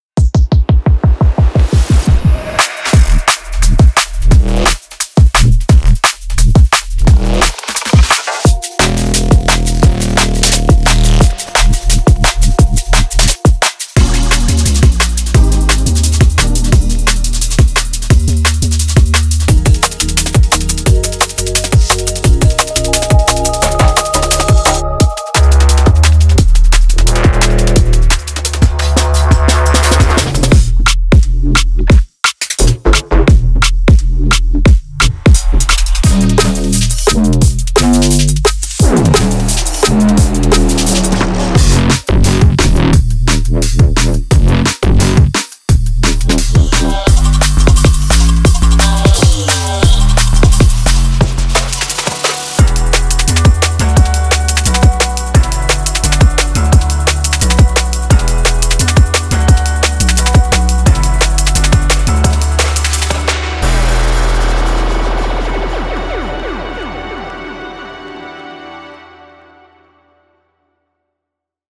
デモサウンドはコチラ↓
Genre:Drum and Bass
21 Bass Loops
23 Drum Loops
14 Synth Pad & Drone Loops